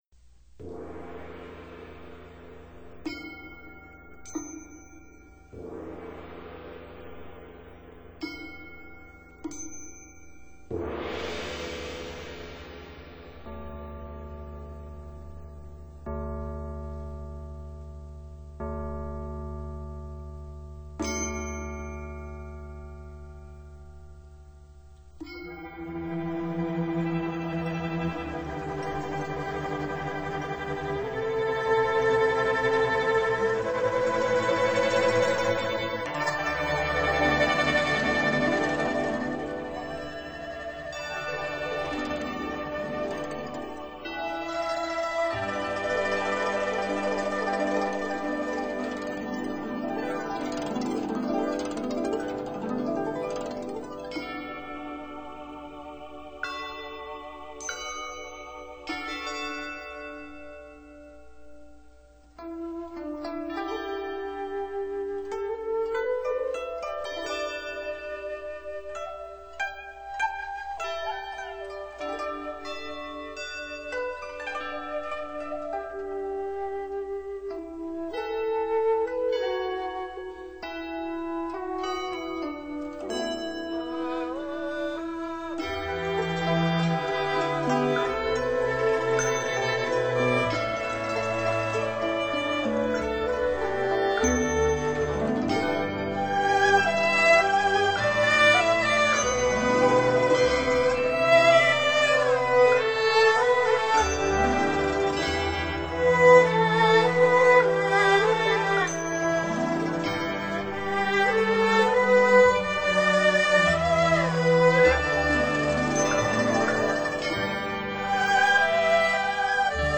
☆以少数民族乐器－－葫芦丝创新演出佛曲，呈现出不同的佛乐风情。